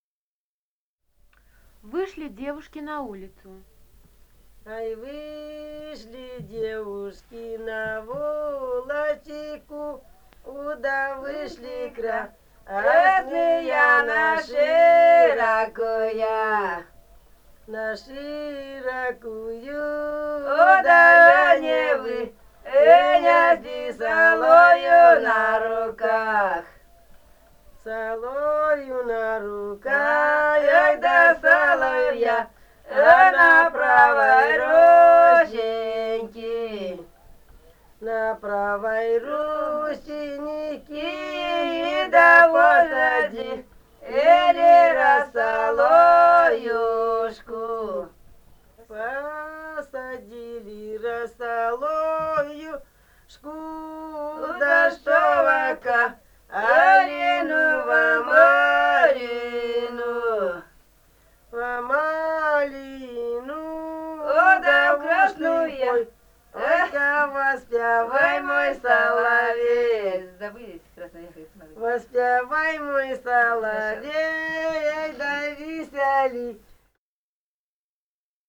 полевые материалы
Алтайский край, с. Маральи Рожки Чарышского района, 1967 г. И1002-07